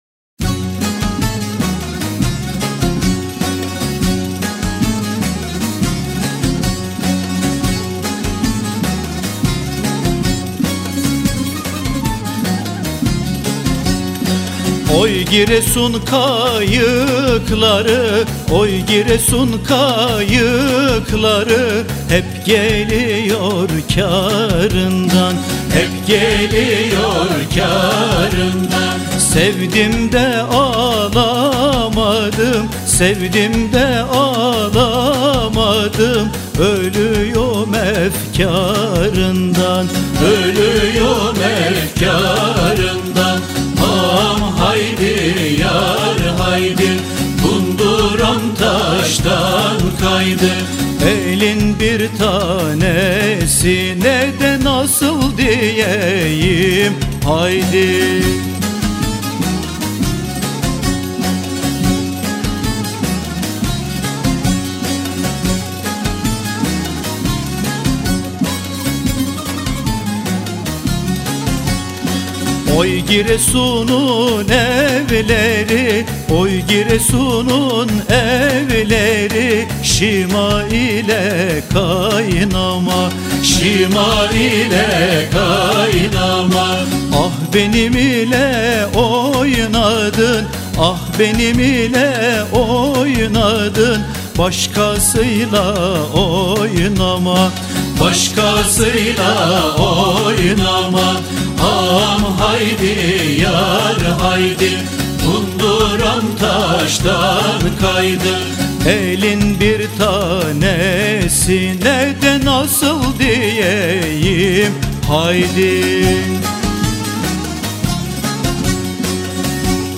Etiketler: türkiye, giresun, türkü